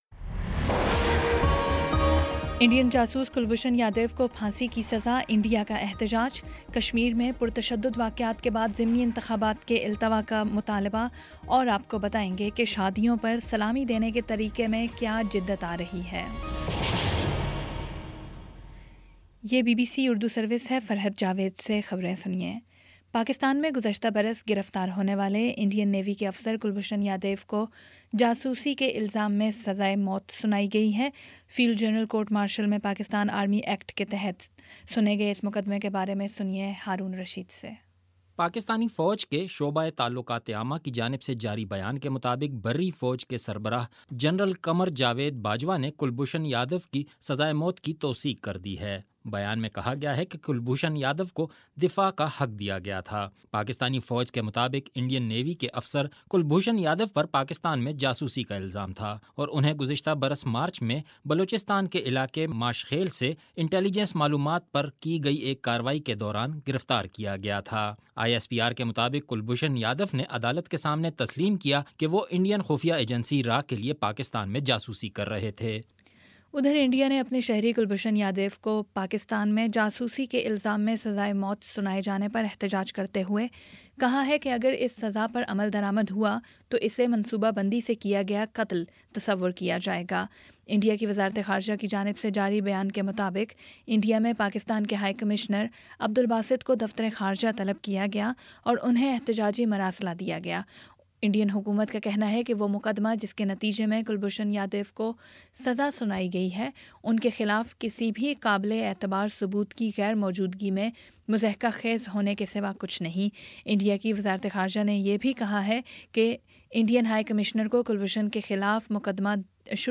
اپریل 10 : شام سات بجے کا نیوز بُلیٹن